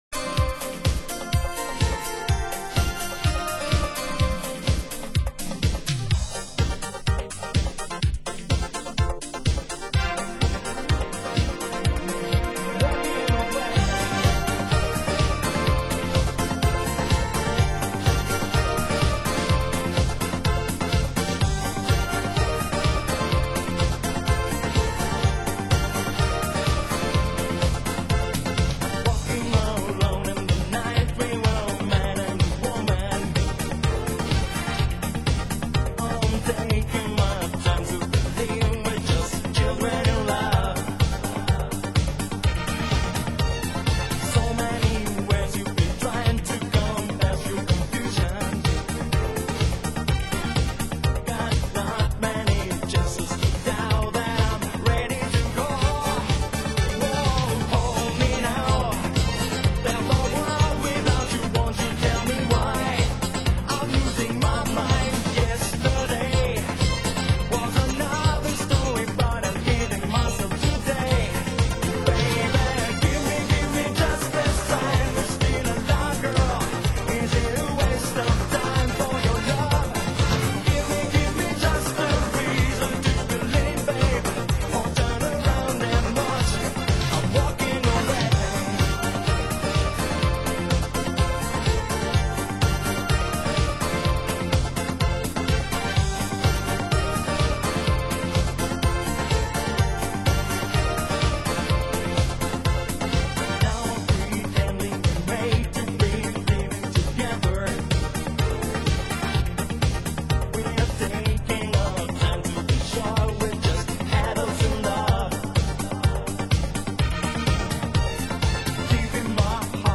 Genre: Italo Disco